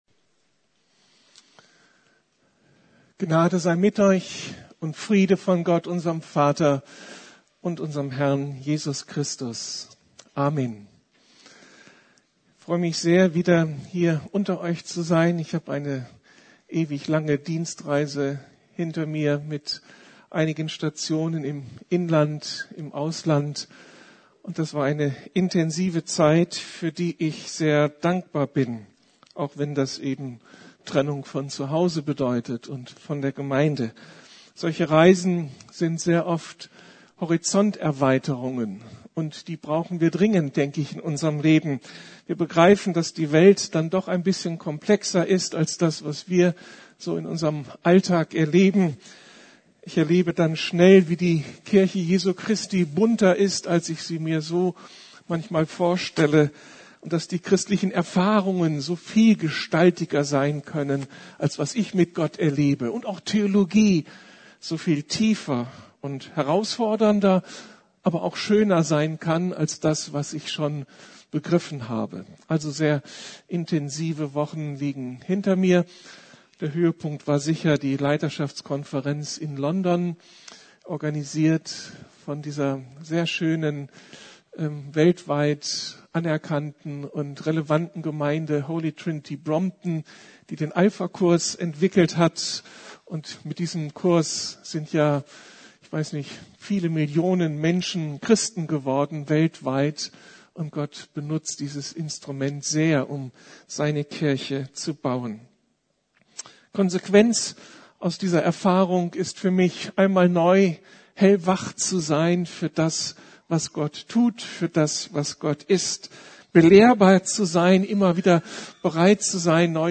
Du brauchst einen Tröster?! ~ Predigten der LUKAS GEMEINDE Podcast